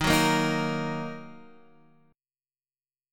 Ebm chord